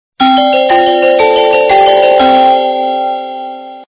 alarm.wav